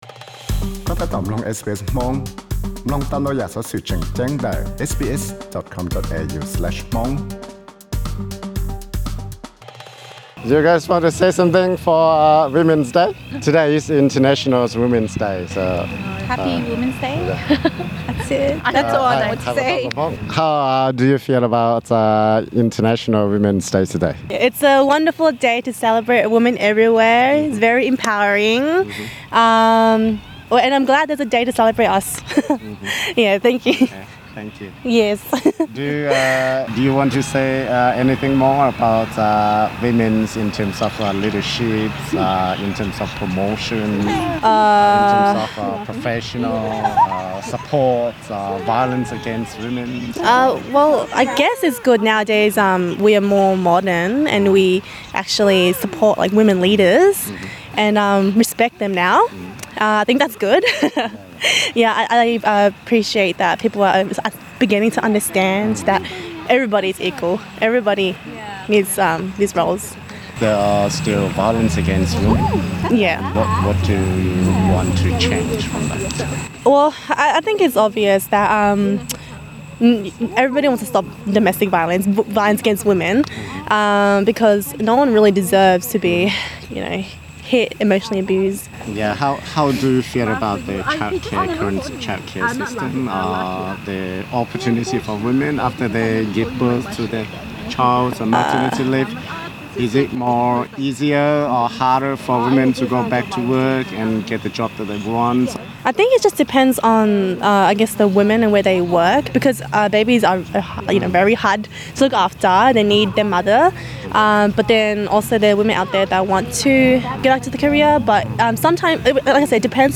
Tej tub koom sib ntawm ntau cov neeg zejzog li Hmoob, Karen thiab Filipino tau tawm tswv tias seb lawv xav li cas rau pej kum haiv poj niam hnub (International Women's Day) uas yog hnub tim 07 lub 3 hlis ntuj xyoo 2020, thaum lawv tau mus koom Hmong Australia Festival 45 xyoos lub koom txoos thov nyiaj pab Australia tej zejzog raug hav zoov kub hnyiab tsis ntev los no thiab pab rau Australia cov neeg tua hluav taws.